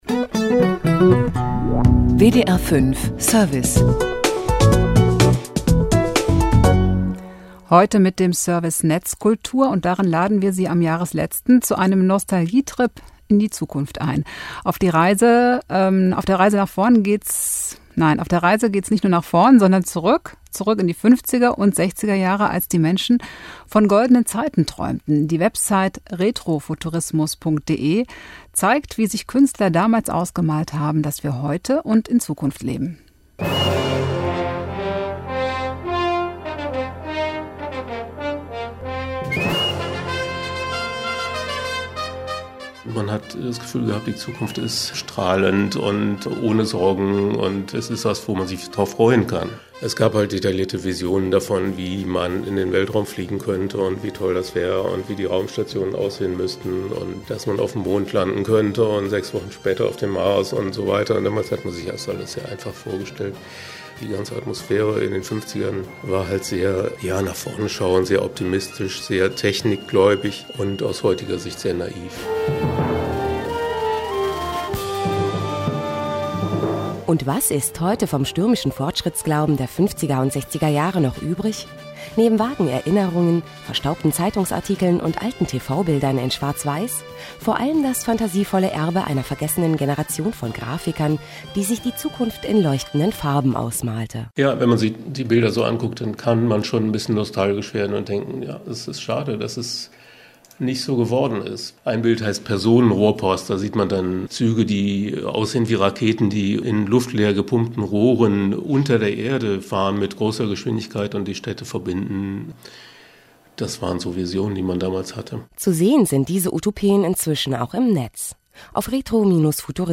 Retro-Futurismus-Interview
wdr5scala_retrofuturismus_interview.mp3